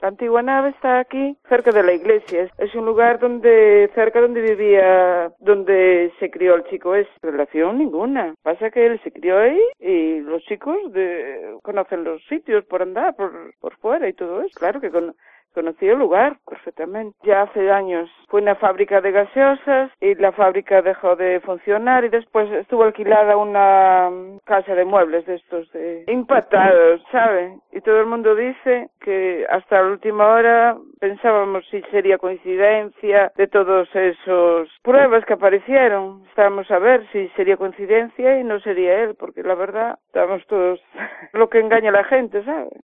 Vecina de Asados en COPE: “La nave está cerca de donde se crio ‘El Chicle’